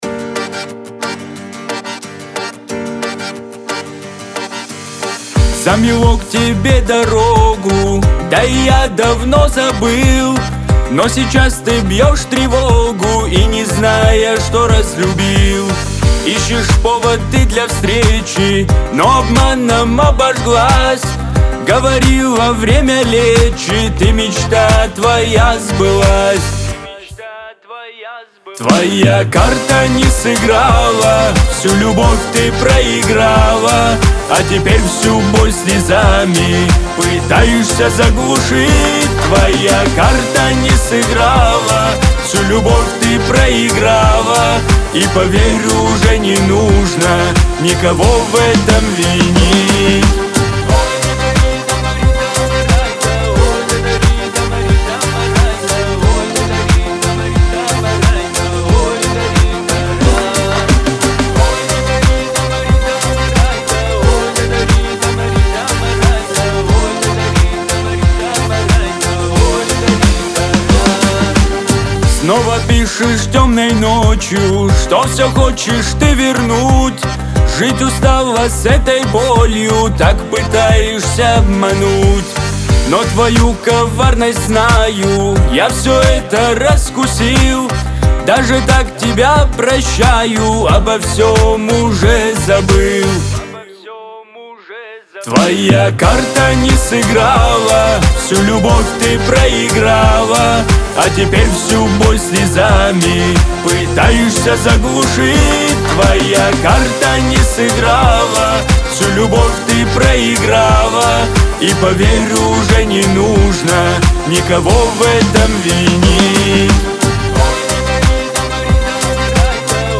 Лирика , Кавказ – поп